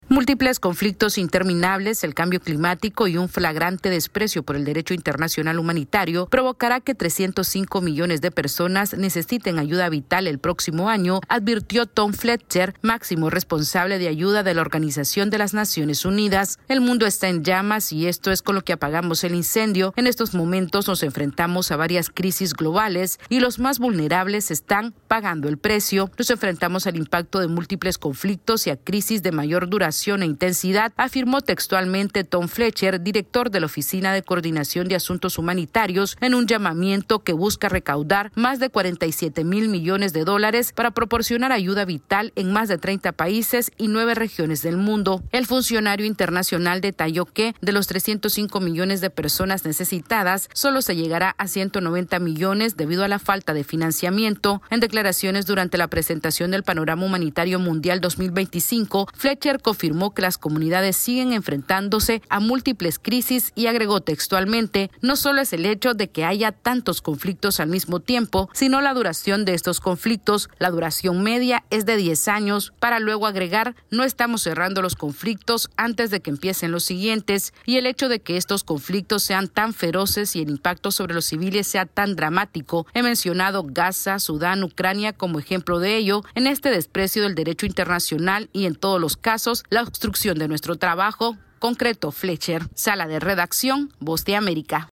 AudioNoticias
La Organización de las Naciones Unidas asegura que más de 300 millones de personas necesitaran ayuda vital el próximo año, debido a los múltiples conflictos que suceden alrededor del mundo. Esta es una actualización de nuestra Sala de Redacción.